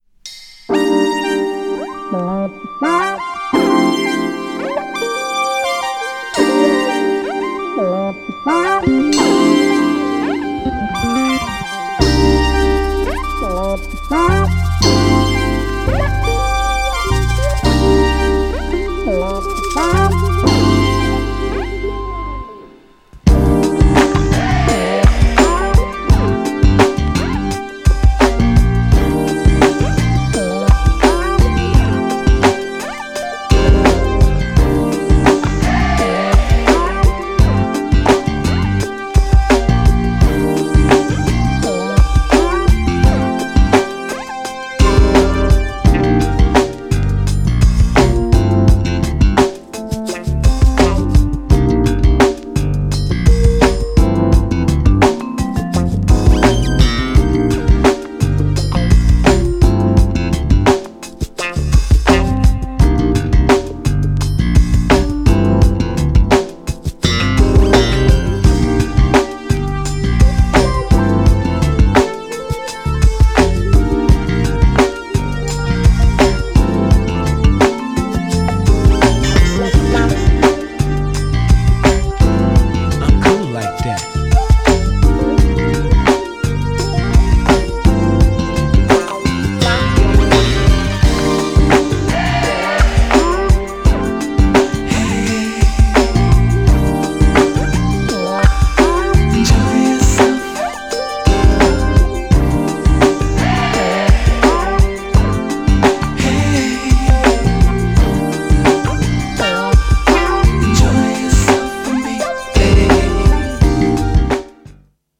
1stアルバムからの冒頭を飾るJAZZY＆アーバンな曲!! 90'sなコンテンポラリーJAZZサウンド!!
GENRE R&B
BPM 81〜85BPM